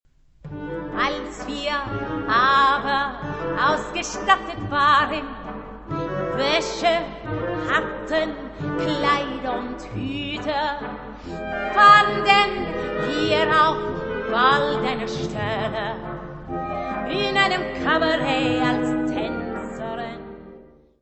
soprano
tenor
barítono
baixo.
: stereo; 12 cm + folheto (31 p.)
Music Category/Genre:  Classical Music